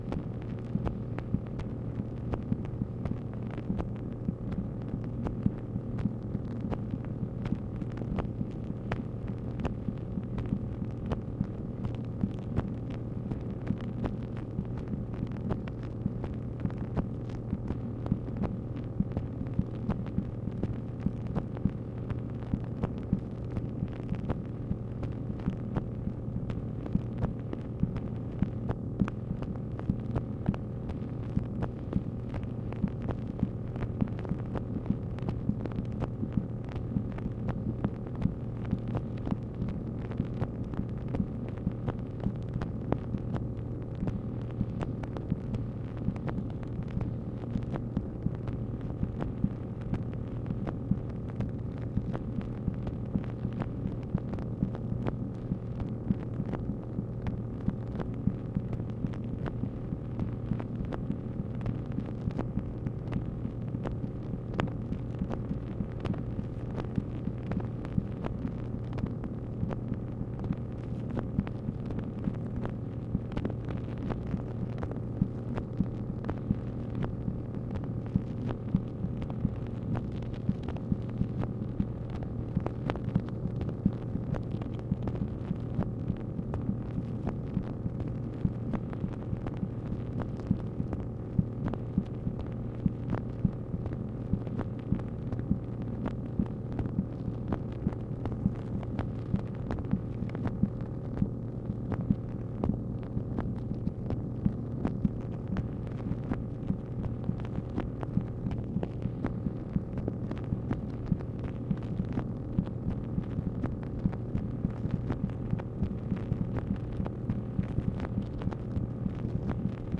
MACHINE NOISE, September 3, 1964